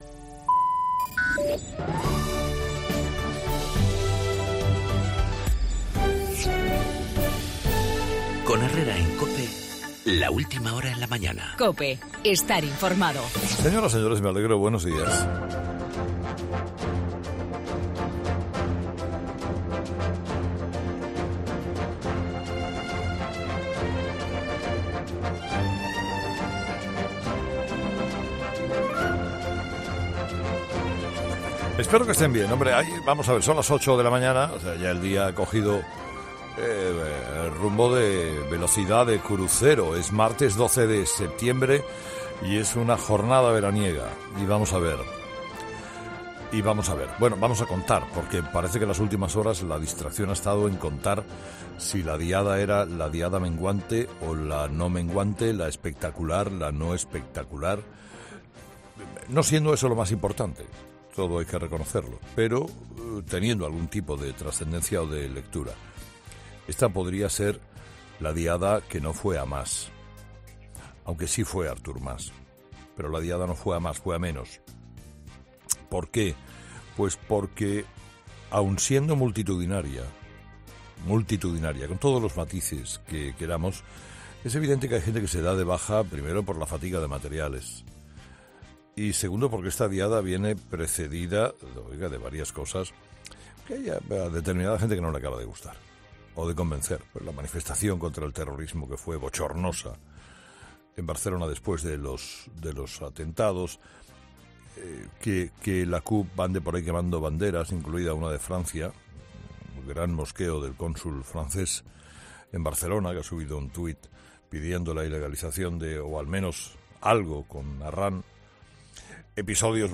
Monólogo de las 8 de Herrera
Carlos Herrera analiza en su monólogo la Diada de Cataluña donde el independentismo salió a la calle para reclamar el sí en el referéndum.